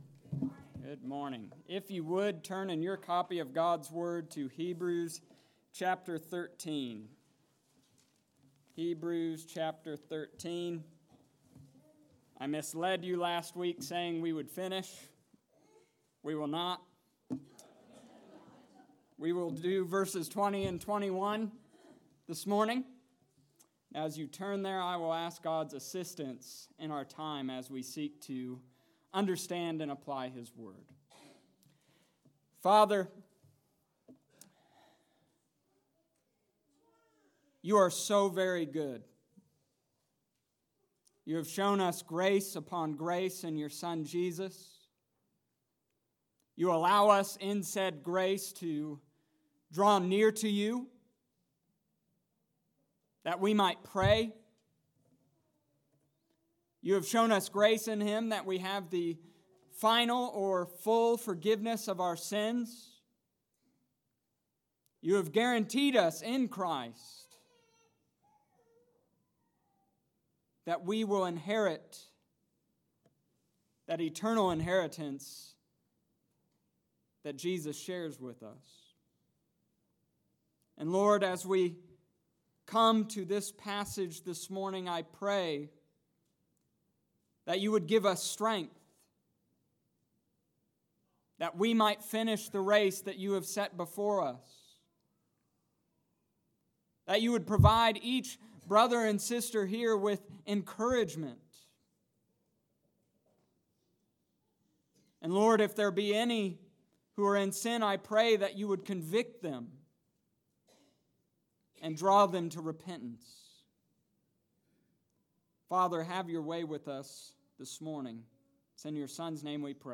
A Prayer for Endurance Hebrews Chapter 13 verses 20-21 August 11th, 2019 Sunday morning service